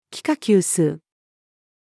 幾何級数-female.mp3